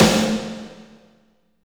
50.10 SNR.wav